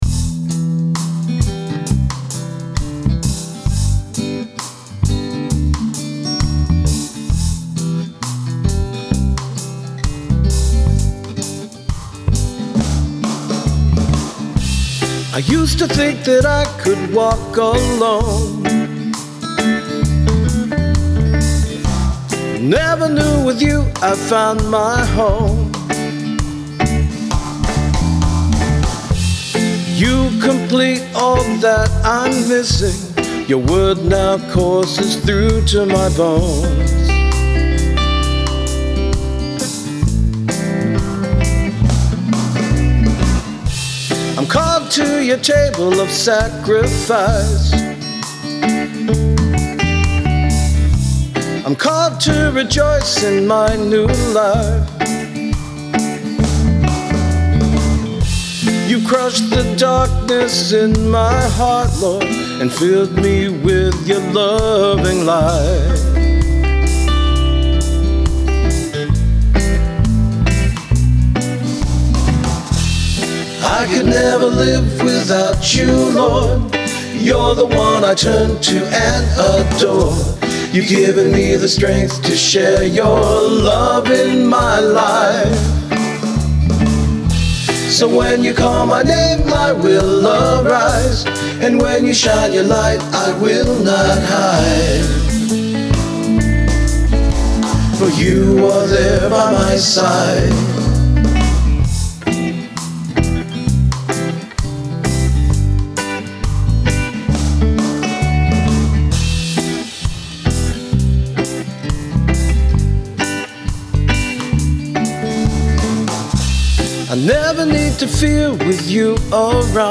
For instance, I just put the finishing touches on a new contemporary christian song demo this morning.
But in the end, all I did was replace my original click track, and added another guitar. I just found that the simple, straight-forward sound just worked better.